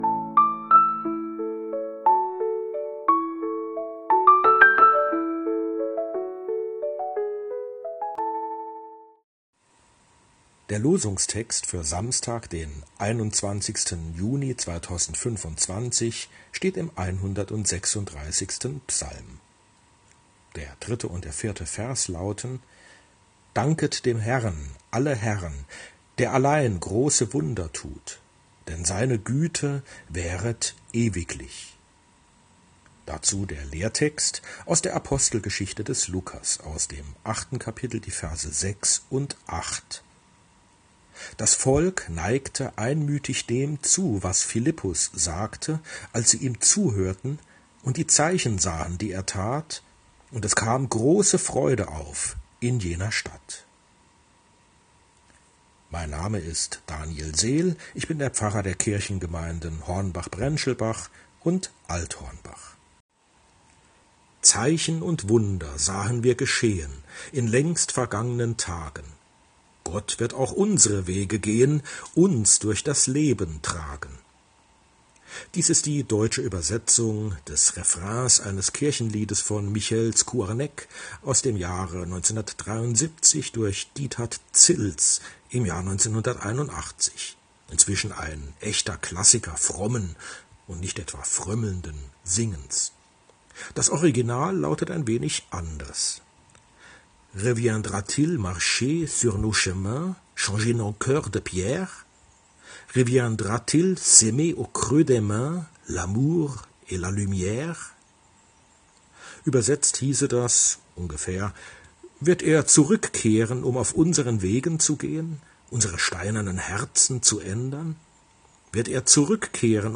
Losungsandacht für Samstag, 21.06.2025